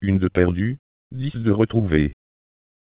Synthese de la parole - le Démonstrateur CNETmultilingue